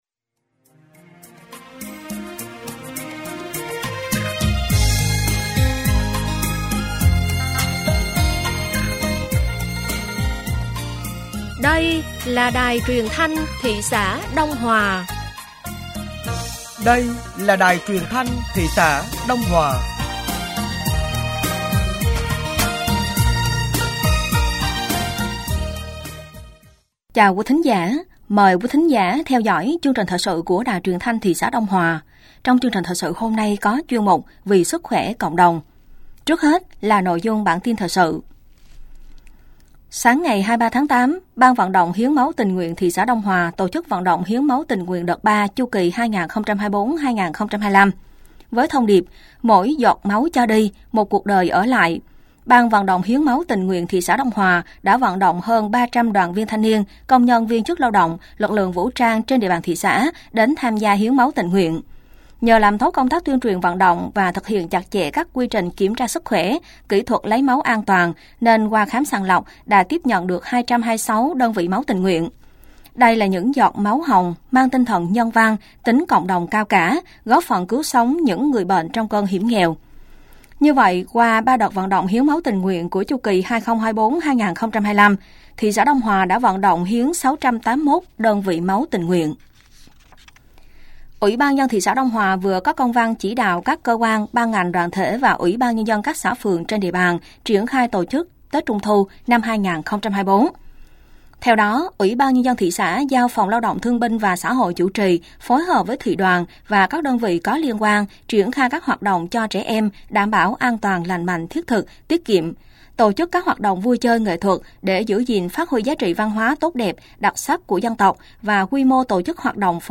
Thời sự tối ngày 23 và sáng ngày 24 tháng 8 năm 2024